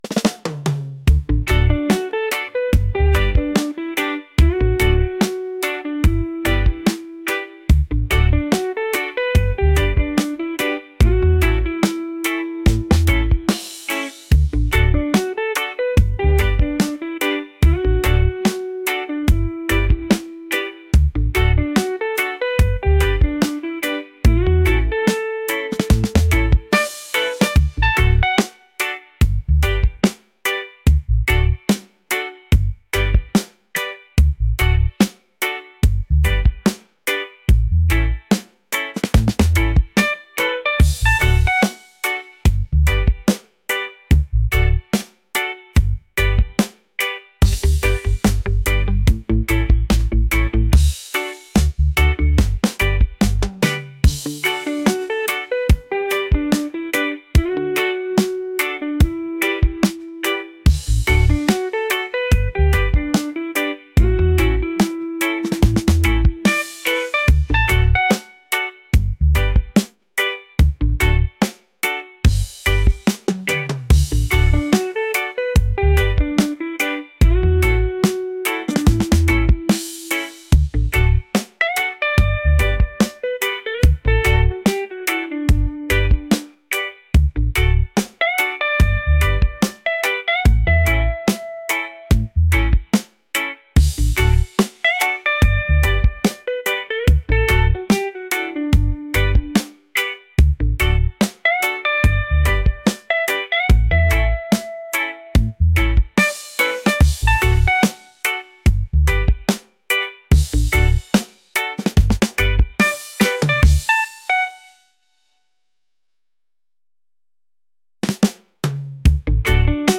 catchy | laid-back | reggae